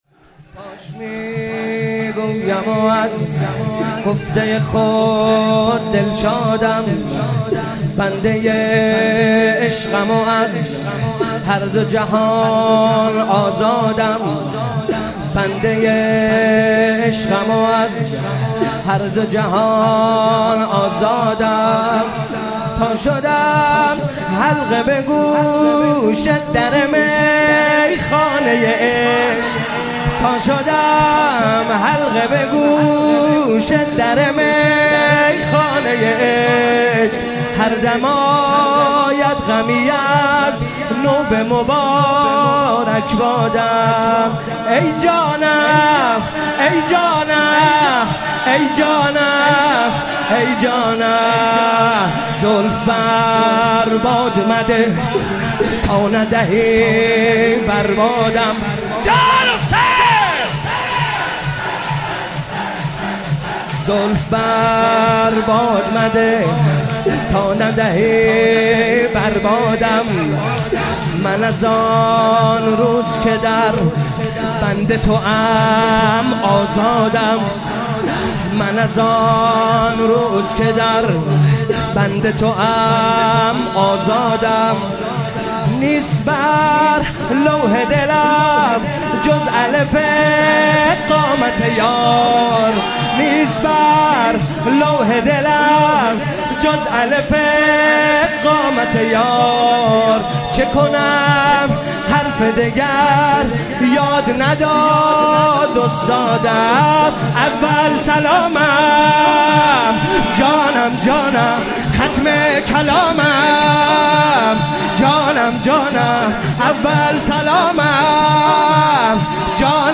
مداحی شب اول محرم 98 محمدرضا طاهری
دانلود مراسم شب اول محرم 95 حاج محمدرضا طاهری